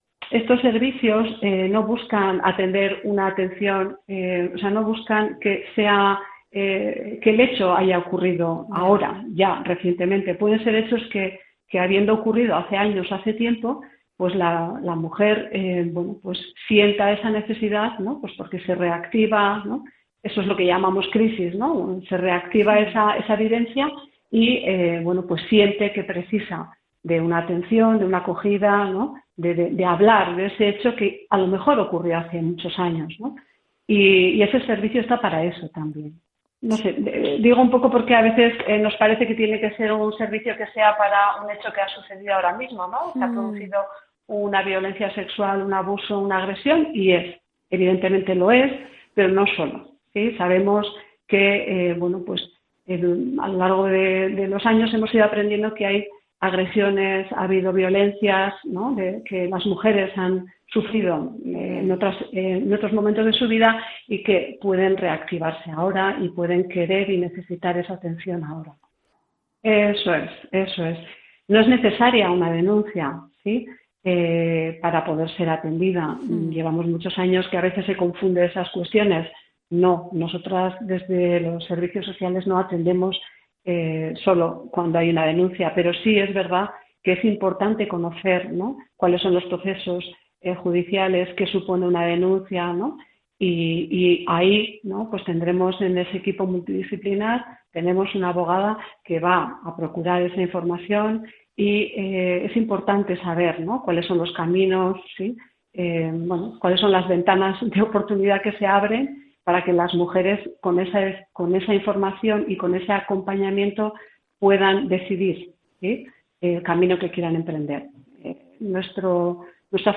"Es importante atender a los deseos y los ritmos de las mujeres, porque puede ser que se trate de una agresión o episodio que vivió hace tiempo y ahora se esté manifestando" explicaba en Gipuzkoako Kale Nagusia de Onda Vasca Belén Larrión, directora general de protección a la infancia, inclusión social y atención a la violencia contra las mujeres.